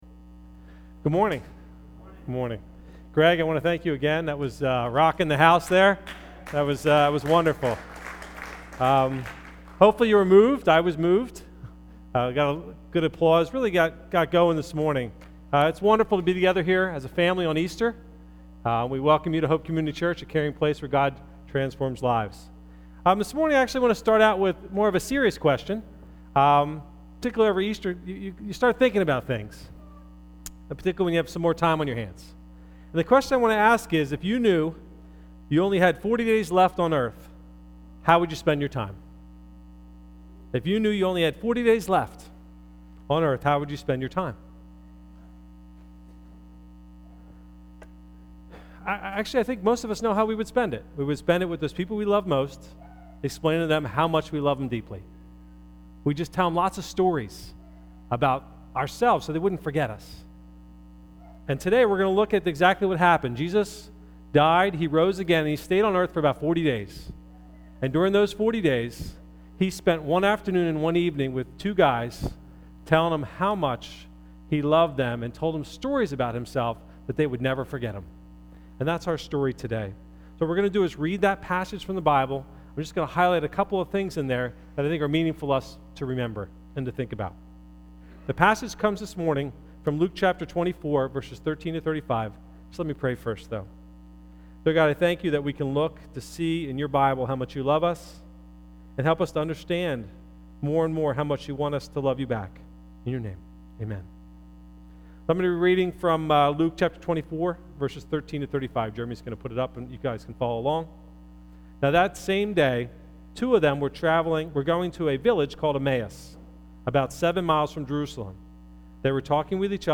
Occasion: Easter Sunday